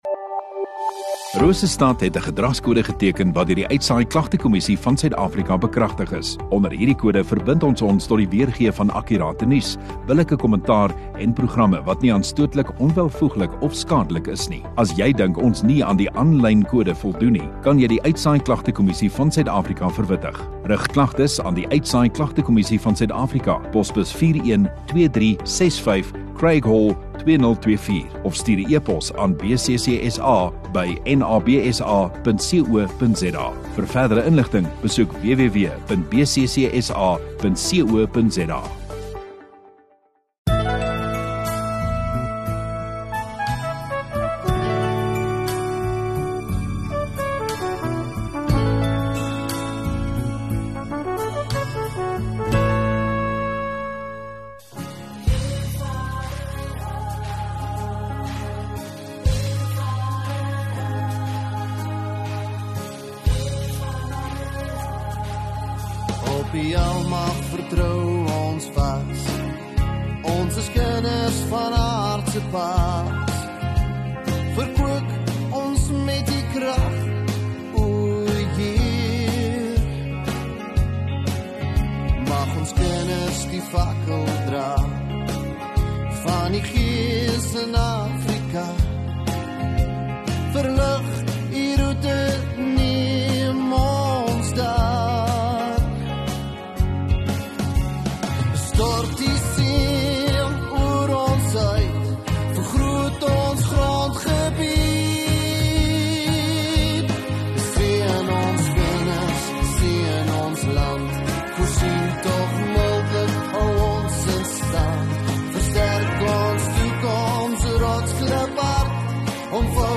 17 Aug Saterdag Oggenddiens